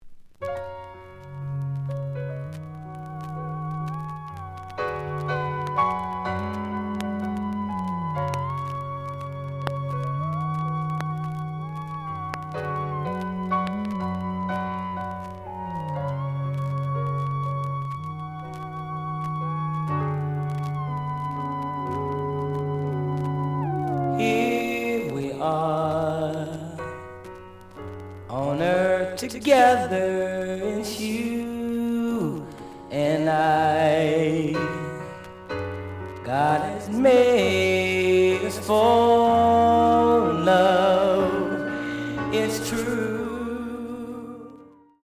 The audio sample is recorded from the actual item.
●Genre: Funk, 70's Funk
Some click noise on both sides, but almost good.)